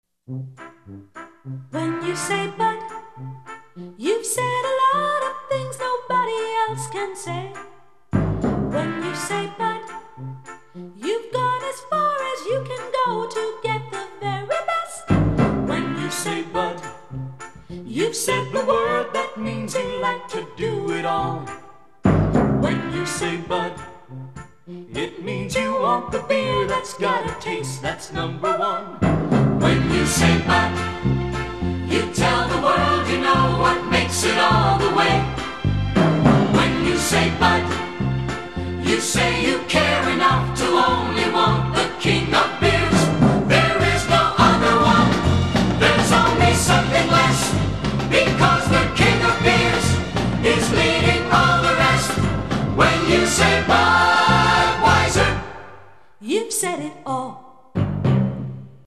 Werbespot 5 706 KB 1588 Sound abspielen!